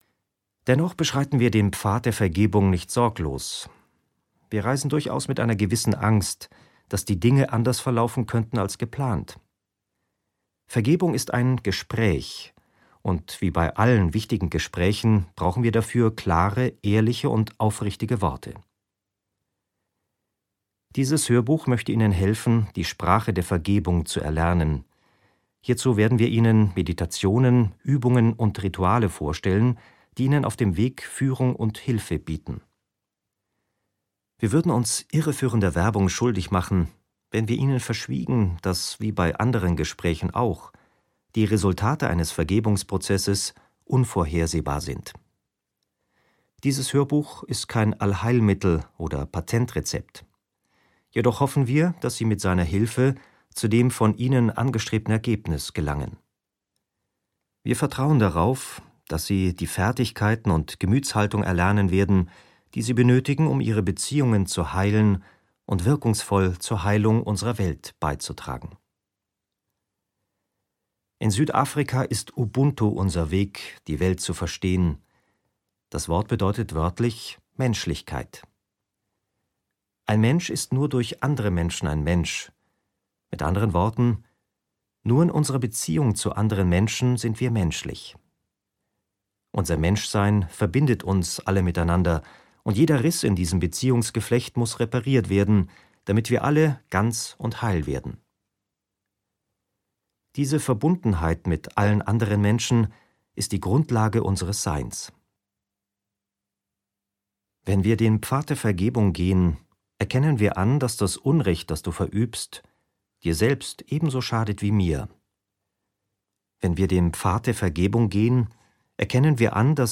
Das Buch des Vergebens - Desmond Tutu - Hörbuch